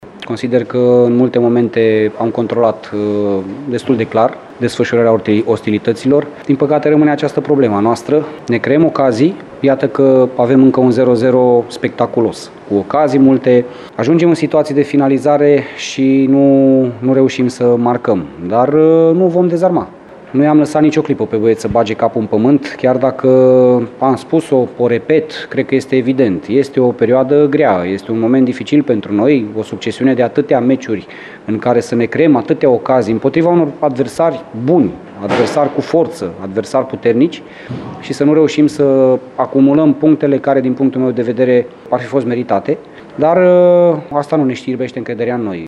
La declarațiile din finalul întâlnirii, ambii antrenori au exprimat aceeași idee – că UTA ar fi trebuit să ia toate punctele din această întâlnire. ”Principalul” arădenilor, Laszlo Balint recunoaște problemele cu inspirația la finalizare, dar adaugă că nu-i lasă pe băieți să se gândească prea mult la regretele ratărilor: